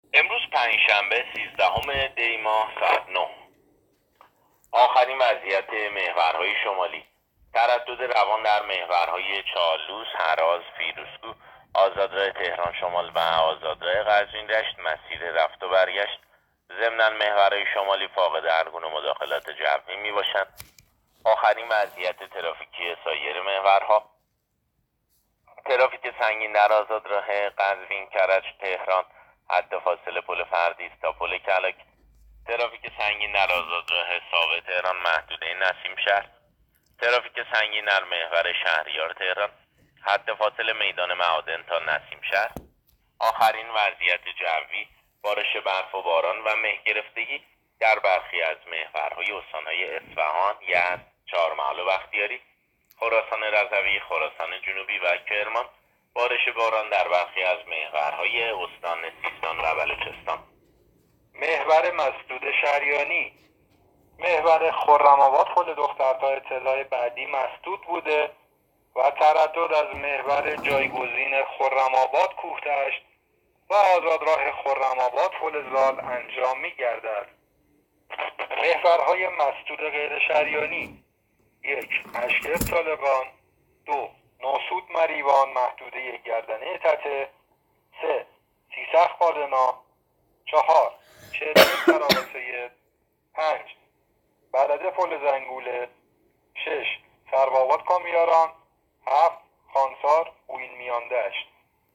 گزارش رادیو اینترنتی از آخرین وضعیت ترافیکی جاده‌ها تا ساعت ۹ سیزدهم دی؛